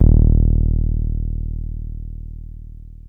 303 D#1 6.wav